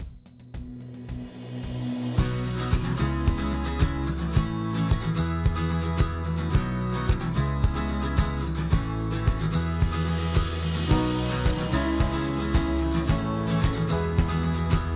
Music-On-Hold Options